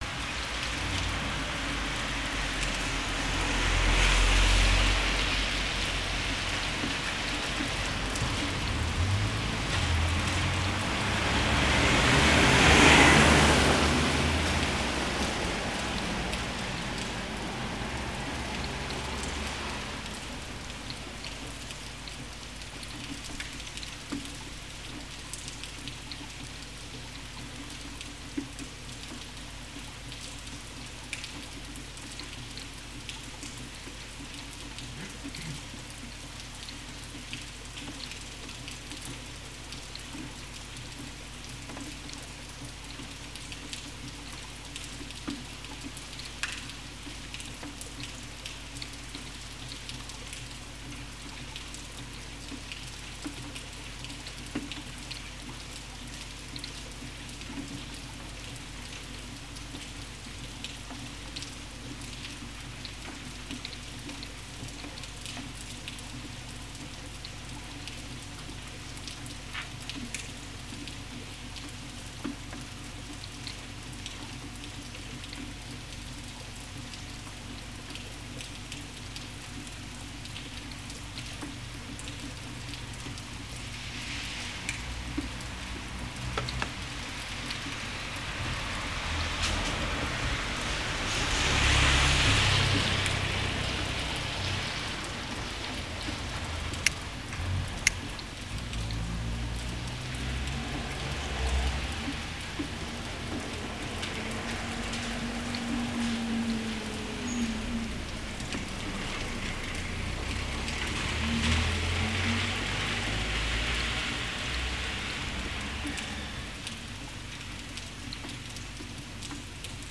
pluie -
il y a eu une sorte de tempête tout à l'heure - et encore maintenant - il continue de pleuvoir - mais plus faiblement -
j'ai quand même posé mon micro à la fenêtre -
des voitures - (je ne supporte plus les voitures) - et les gouttes de pluie -
ext_pluie_23h.mp3